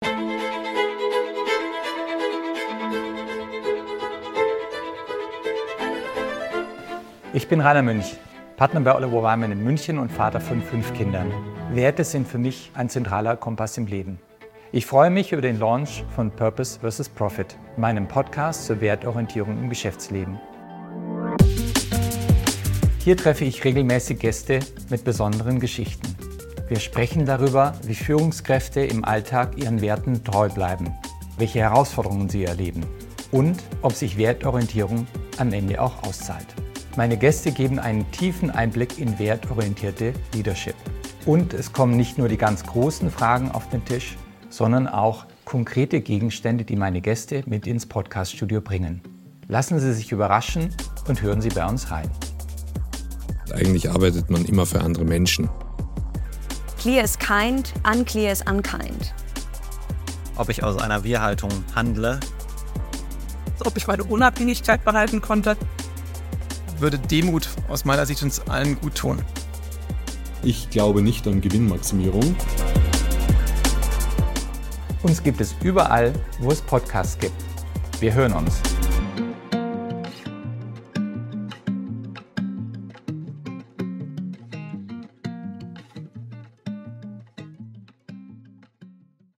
Purpose vs. Profit ist ein Interview-Podcast zur Werteorientierung im Geschäftsleben.